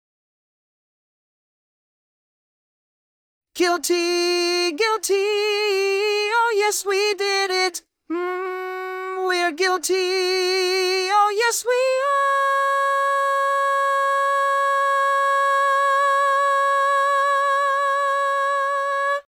Key written in: D♭ Major
Type: Barbershop
Each recording below is single part only.